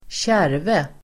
Ladda ner uttalet
kärve substantiv, sheaf Uttal: [²tj'är:ve] Böjningar: kärven, kärvar Definition: bunt el. knippe med (otröskad) säd sheaf substantiv, kärve , sädeskärve Böjningar: sheaves [plural] Förklaring: bunt eller knippe med (otröskad) säd